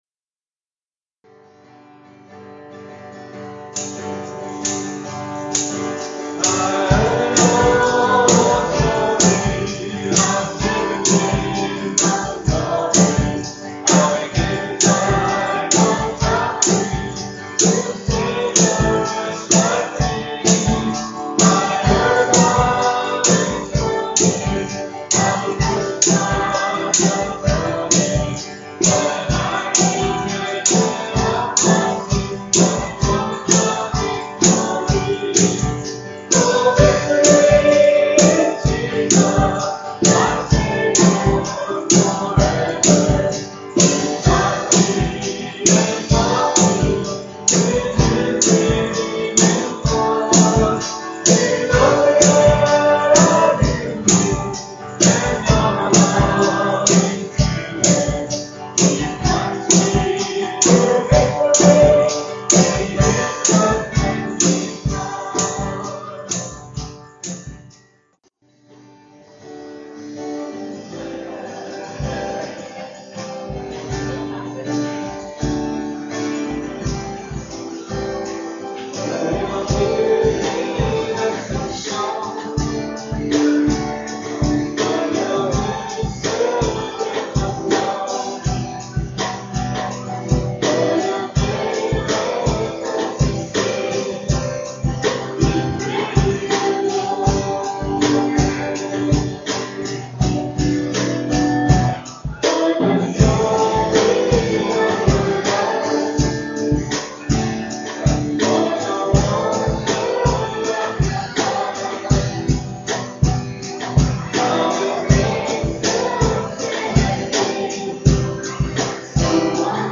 PLAY Joshua: Courageous Faith, Part 1, January 8, 2012 Scripture: Joshua 1:1-9. Message
at Ewa Beach Baptist Church